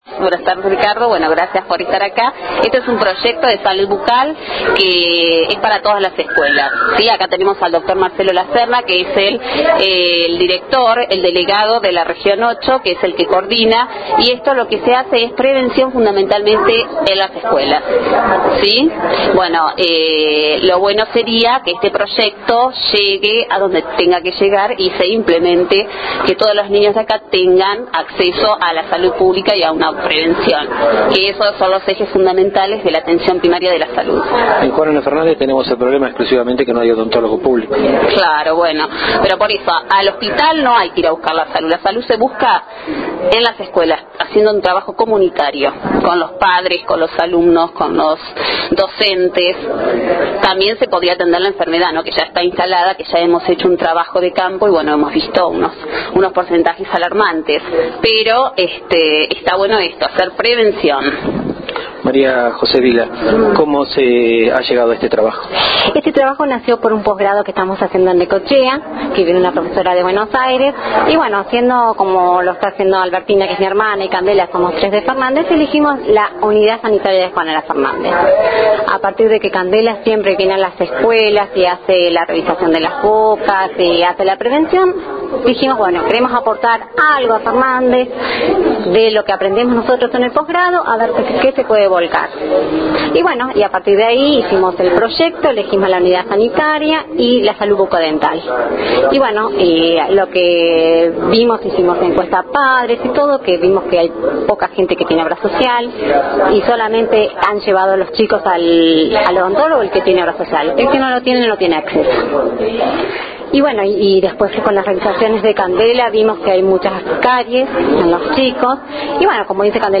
Luego de la exposición, hablamos primeramente con las tres profesionales (tres fotos anteriores), autoras de este proyecto, quienes destacaron la delicada situación de los niños, la mayoría sin cobertura social y sin atención odontológica, también la necesidad de poder aportar con este proyecto algo que, si se concreta, sería valiosísimo para el pueblo y por último el apoyo que recibieron del municipio, de las escuelas y de la gente en general.